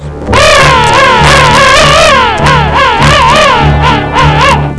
Sound 2: A King penguin demonstrating his prowess.
king_call.wav